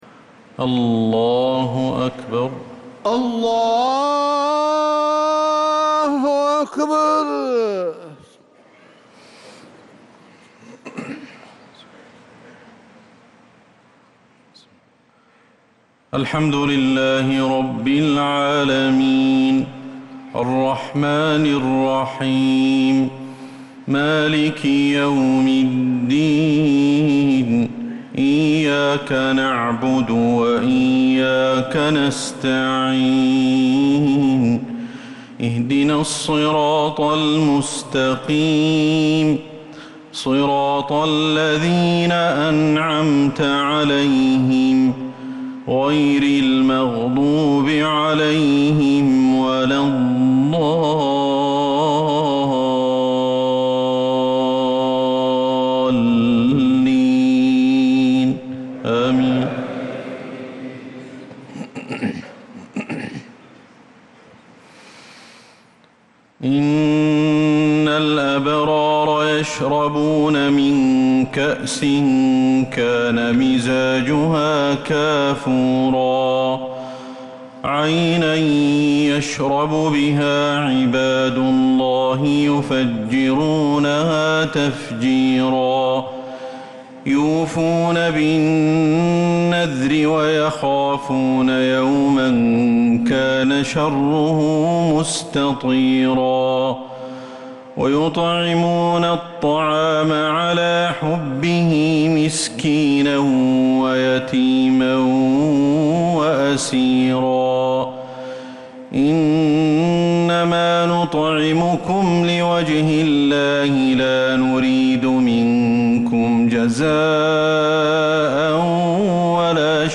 صلاة العشاء للقارئ أحمد الحذيفي 20 ذو الحجة 1445 هـ
تِلَاوَات الْحَرَمَيْن .